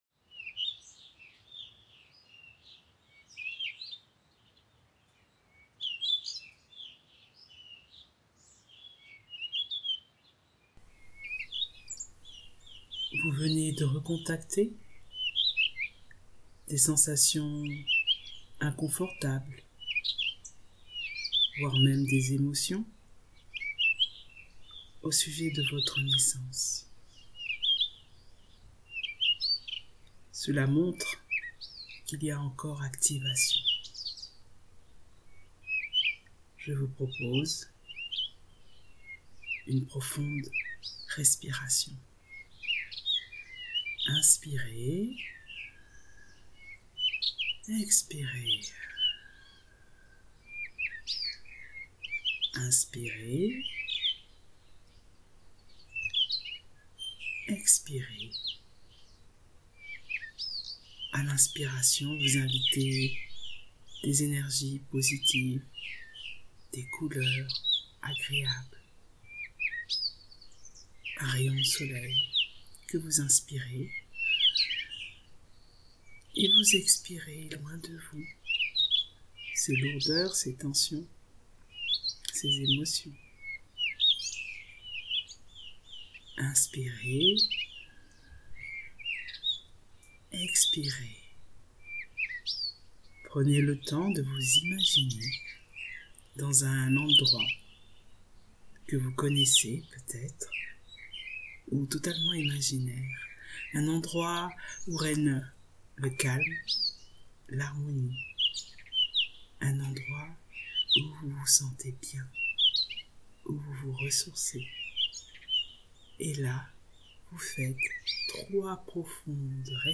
avec CONSTELL’HARMONIE Une relaxation pour se remplir d’ondes positives après ce contact difficile avec les émotions de séparation à la naissance.